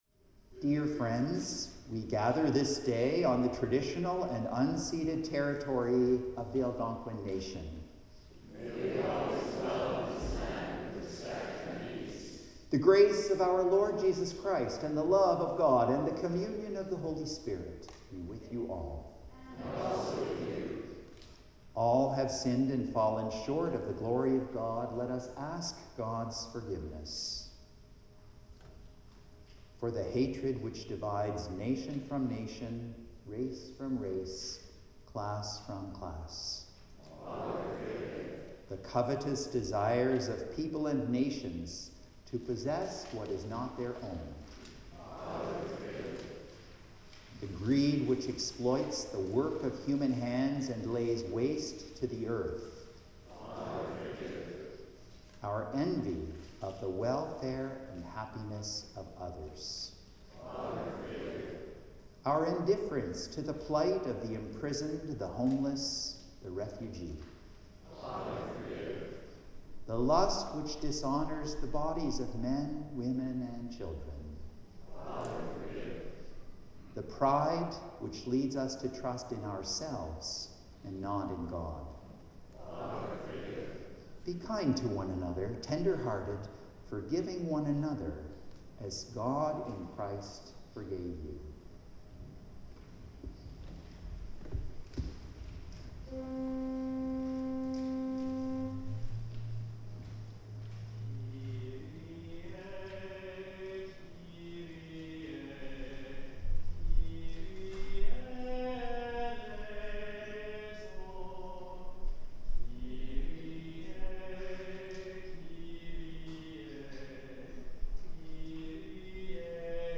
Litany of Reconciliation & Kyrie
Hymn 583
Anthem
The Lord’s Prayer (sung)